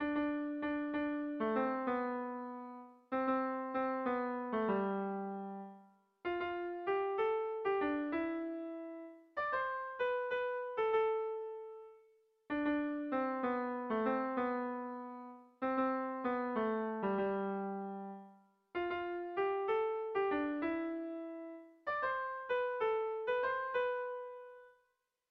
Sentimenduzkoa
ABAB